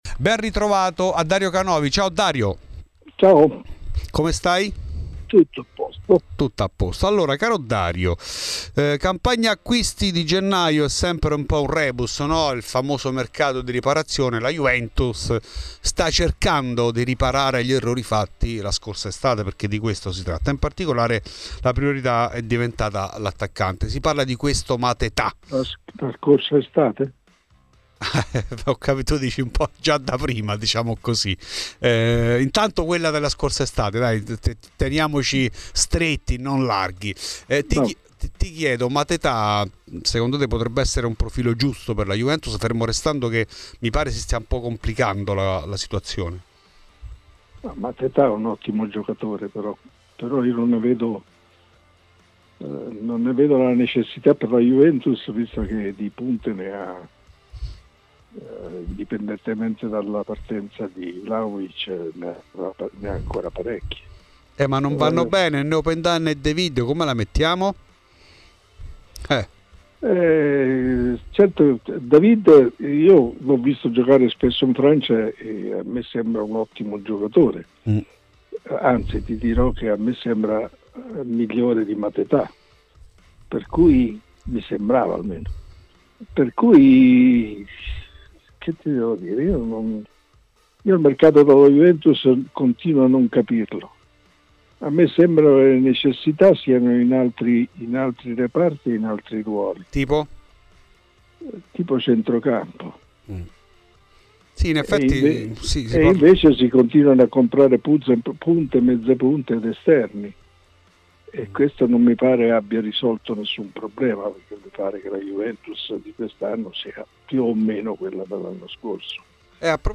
A Fuori di Juve, trasmissione di Radio Bianconera, &egrave